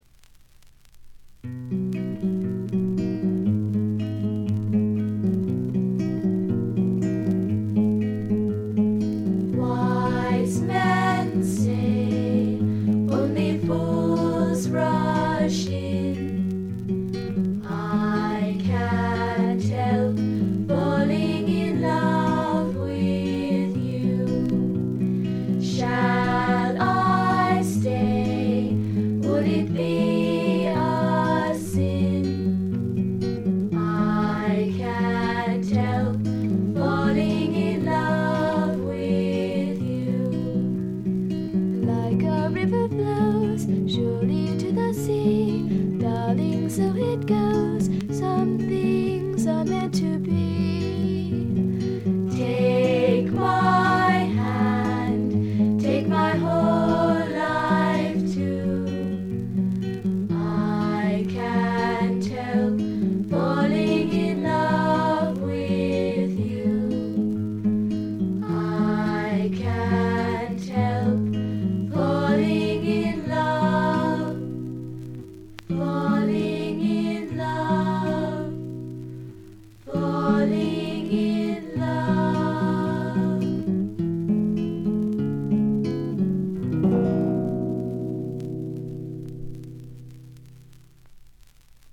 ホーム > レコード：米国 女性SSW / フォーク
軽微なバックグラウンドノイズがずっと出ていますが鑑賞を妨げるようなものではありません。
有名曲中心のカヴァーをアコースティックギター1本の伴奏で清楚に歌います。
試聴曲は現品からの取り込み音源です。